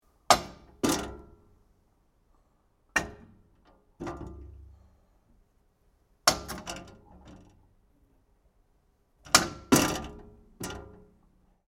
Recsi weighing scales